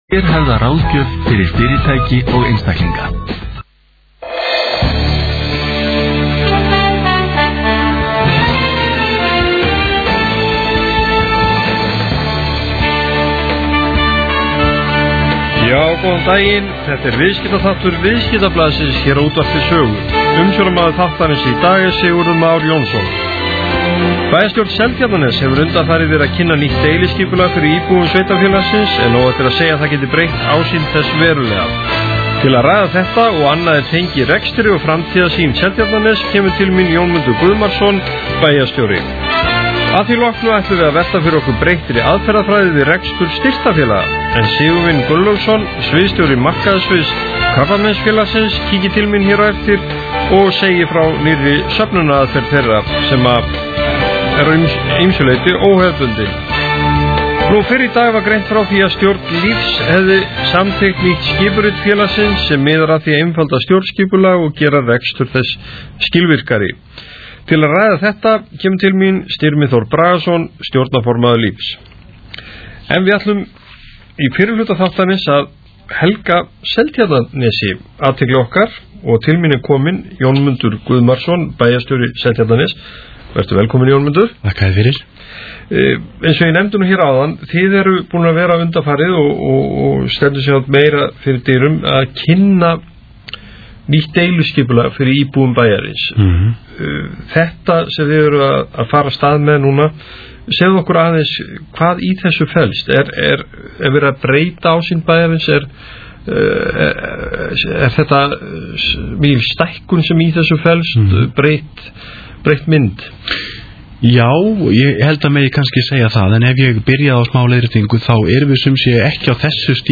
Í viðtali við Jónmund Guðmarsson bæjarstjóra var farið yfir skipulagsmál og niðurstöður ársreikninga ásamt fleiru fróðlegu.